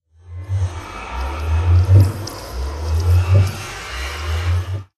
portal.mp3